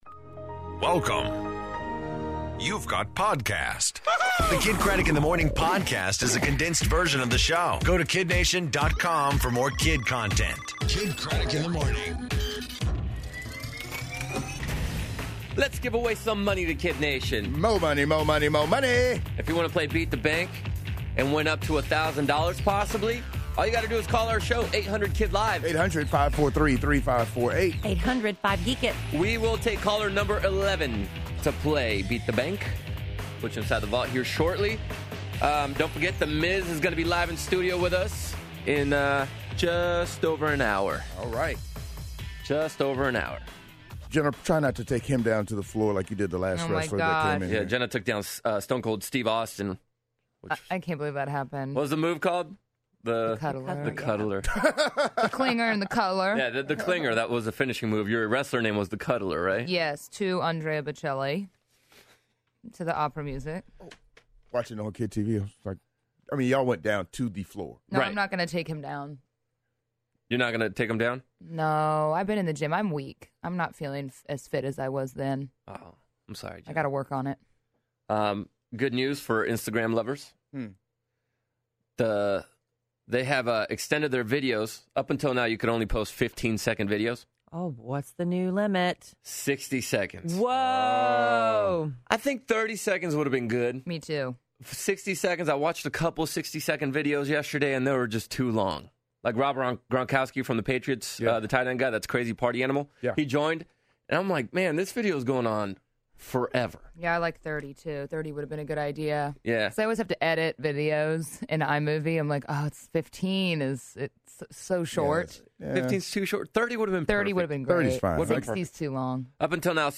And The Miz In Studio